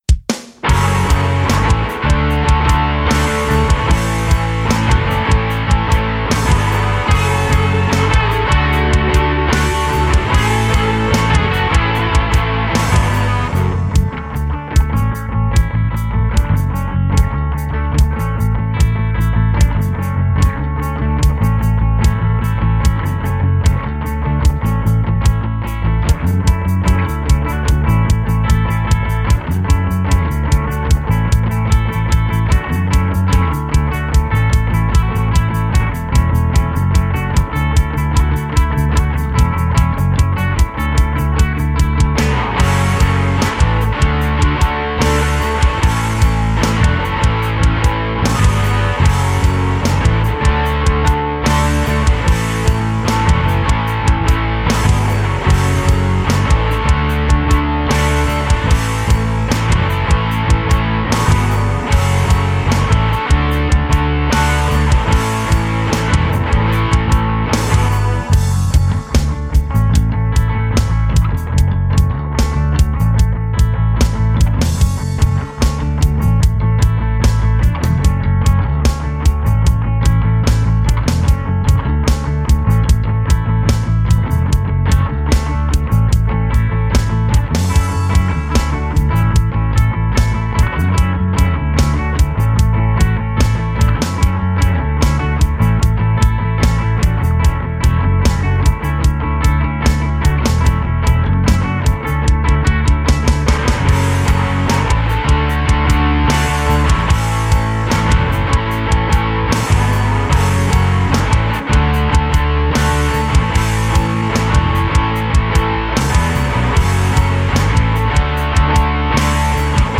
Without Backing Vocals. Professional Karaoke Backing Tracks.
This is an instrumental backing track cover.
Key – A
No Fade